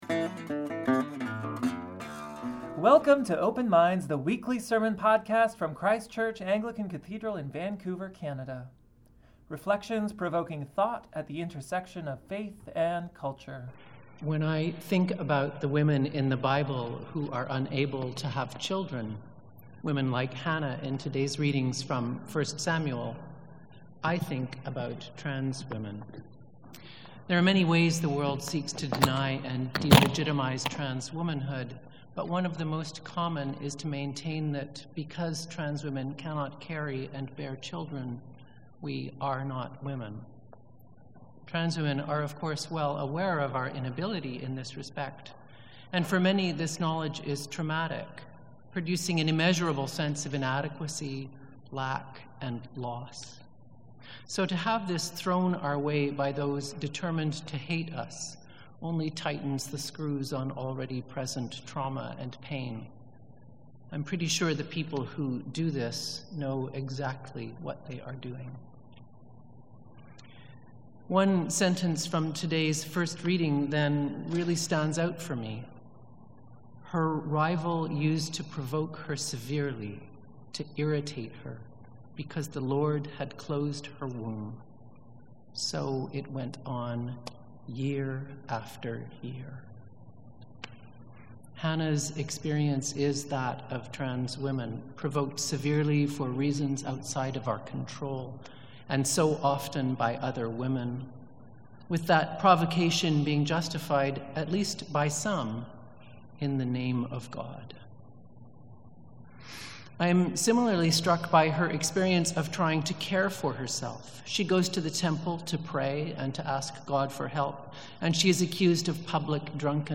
A sermon for Transgender Day of Remembrance
5.30pm St. Brigid's Eucharist